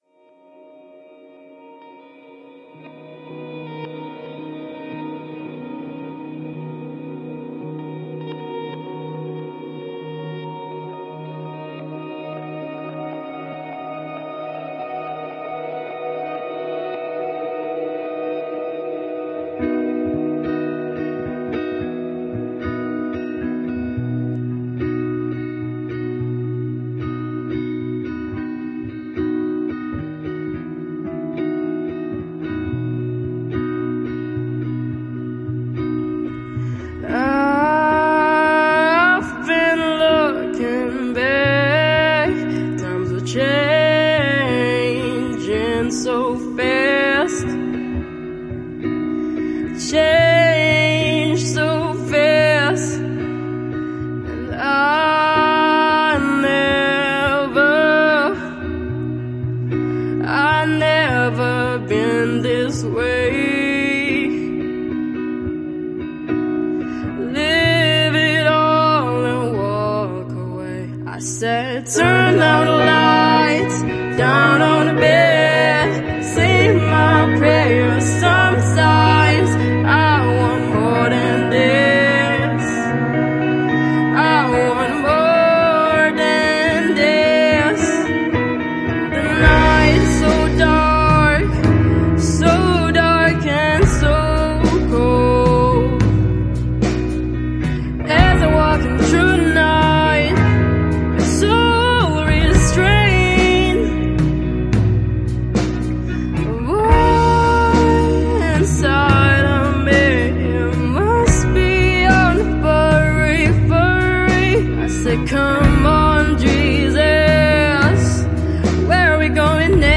Alternative • Bogor